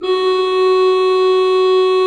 Index of /90_sSampleCDs/Propeller Island - Cathedral Organ/Partition G/HOLZGEDAKT M